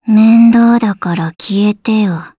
Gremory-Vox_Skill2_jp.wav